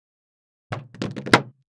Index of /traerlab/AnalogousNonSpeech/assets/stimuli_demos/jittered_impacts/large_styrofoam_longthin_foamroller